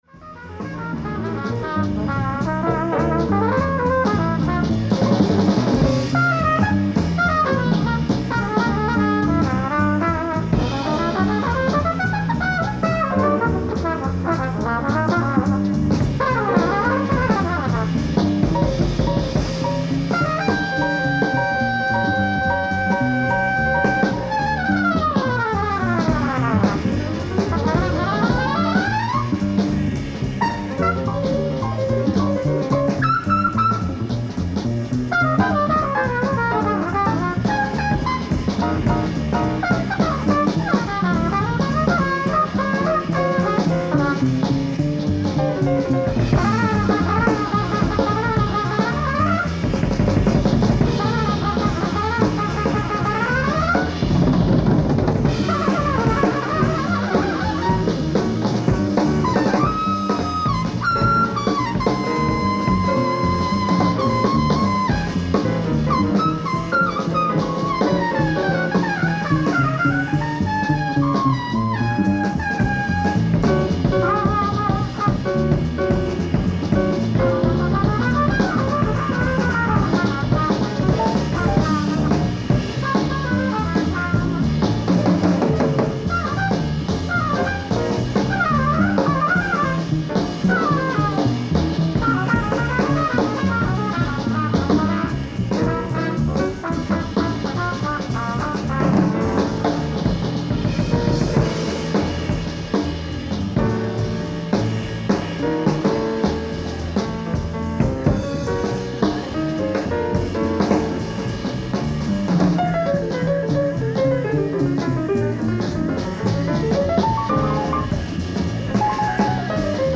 LIVE AT QUEEN MARY JAZZ FESTIVAL, LONG BEACH